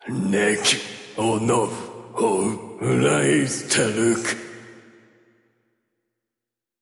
Voice Lines
This section contains Dragon Language.